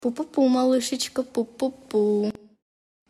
pu pu pu Meme Sound Effect
pu pu pu.mp3